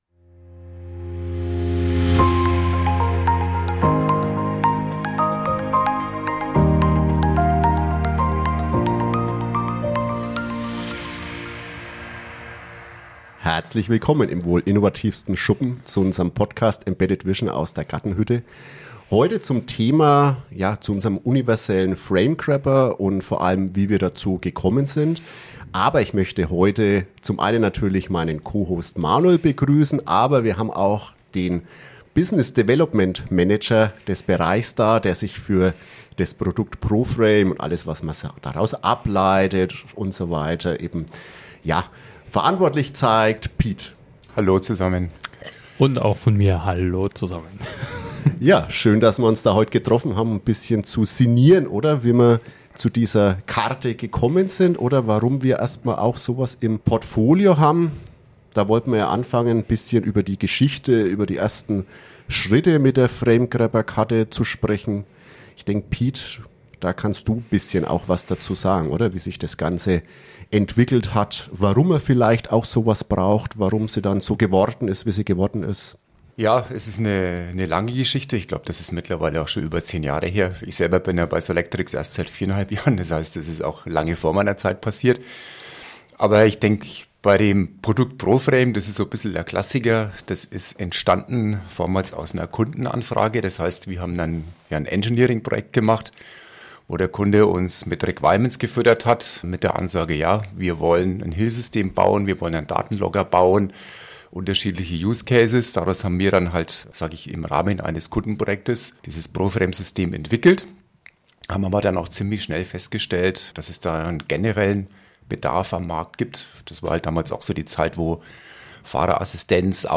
als Gast im Studio